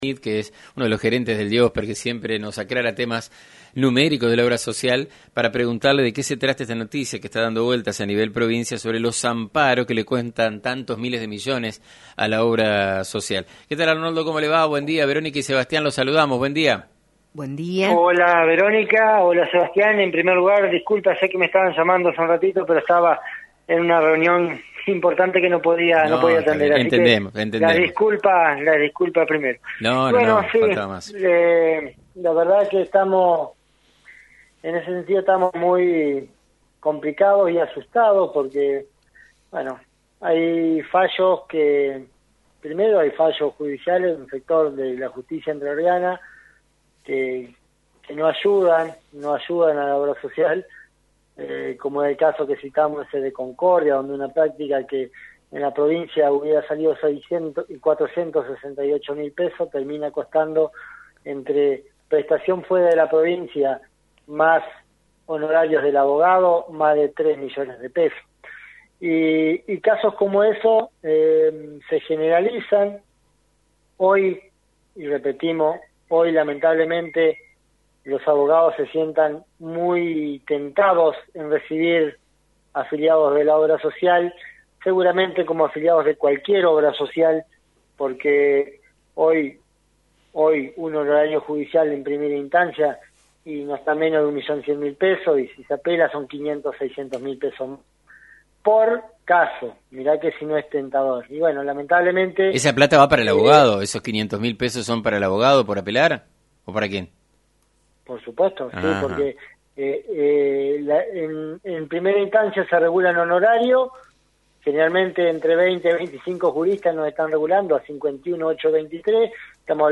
En una reciente entrevista por FM90.3